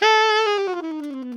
Index of /90_sSampleCDs/Giga Samples Collection/Sax/ALTO DOUBLE
ALTOLNGFLA 3.wav